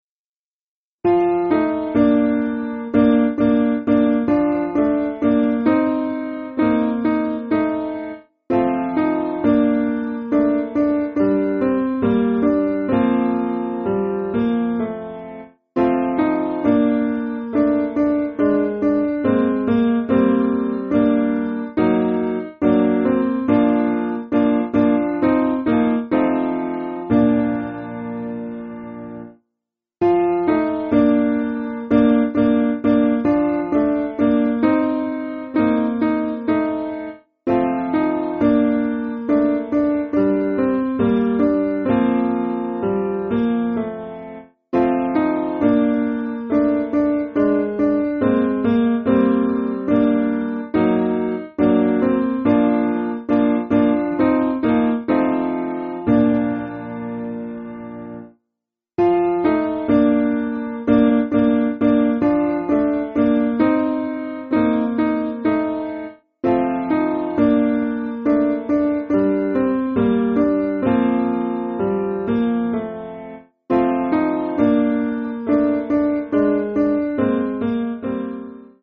Simple Piano
(CM)   3/Bb